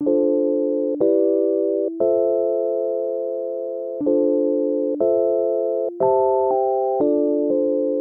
罗德的节奏
描述：120 bpm的循环，可用于RnB或HipHop。
Tag: 120 bpm RnB Loops Piano Loops 1.35 MB wav Key : Unknown